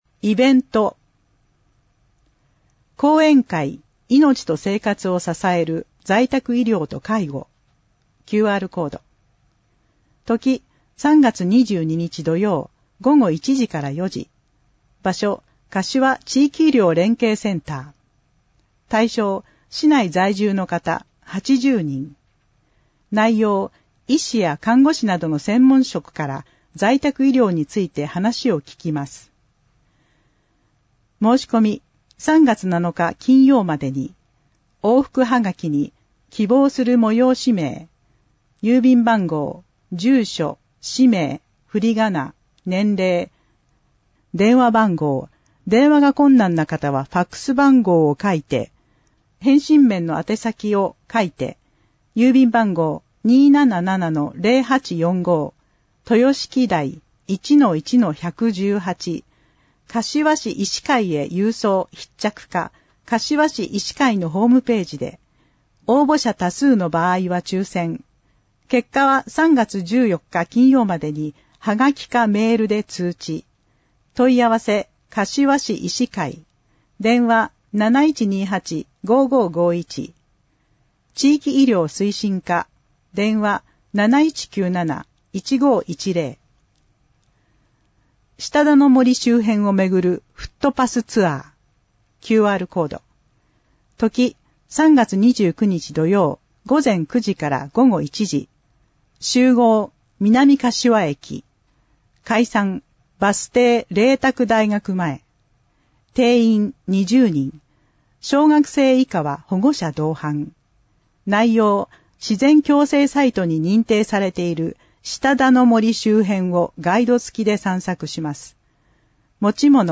令和7年(2025年)3月号音訳版
広報かしわの内容を音声で収録した「広報かしわ音訳版」を発行しています。
発行は、 柏市朗読奉仕サークル にご協力いただき、毎号行っています。